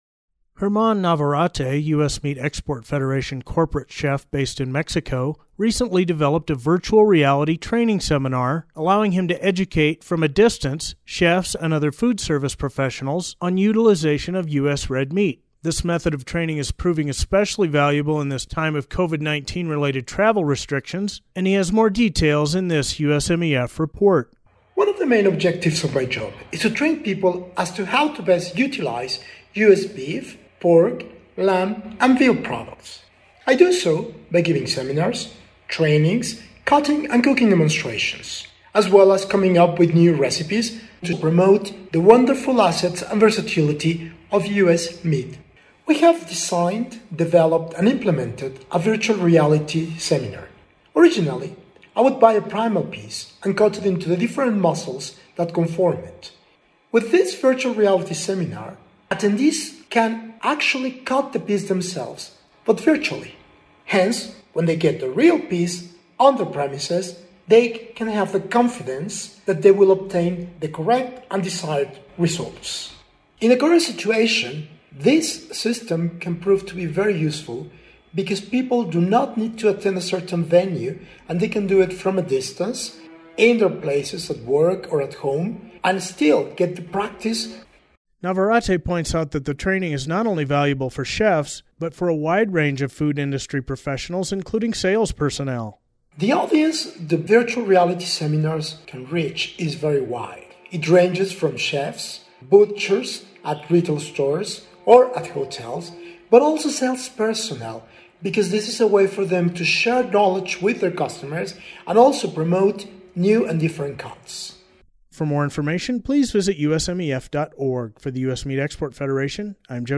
In the attached audio report